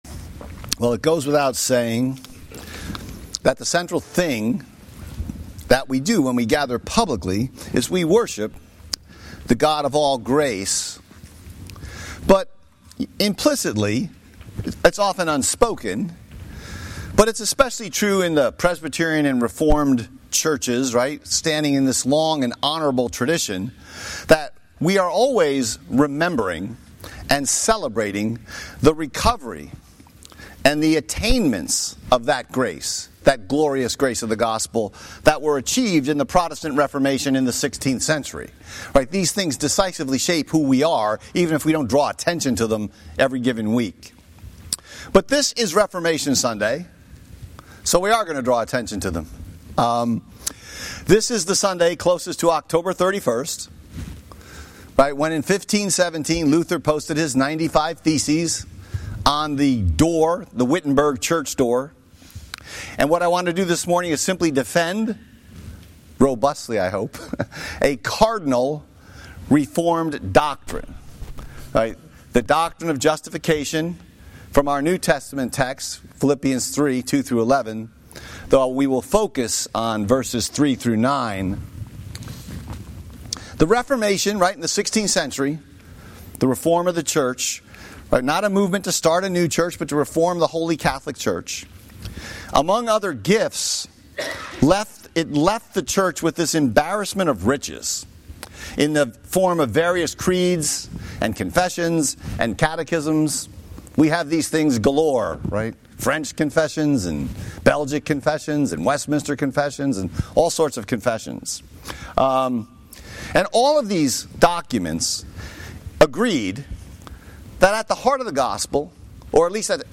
Rubbish and Righteousness - Covenant Presbyterian Church